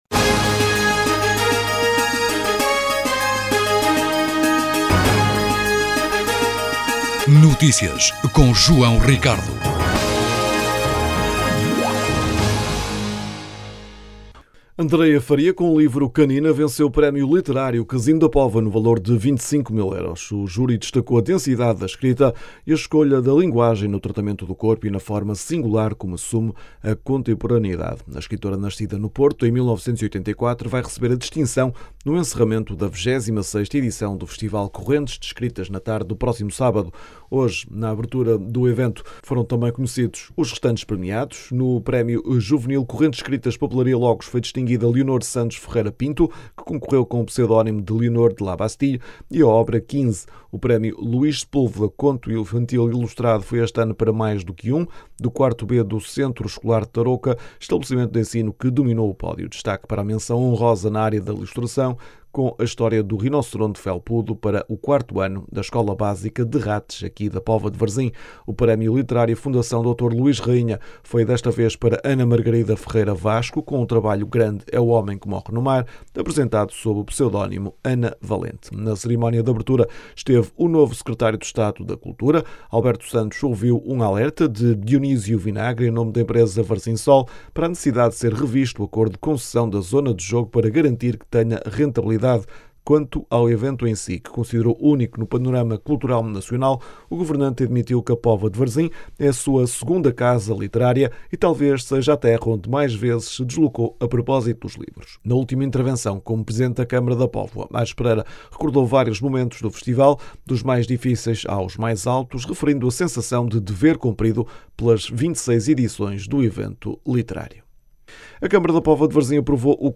O presidente da Câmara revelou que a intervenção está agora numa segunda fase e vai continuar nas próximas semanas, levando a condicionalismos no espaço nos próximos tempos. As declarações podem ser ouvidas na edição local.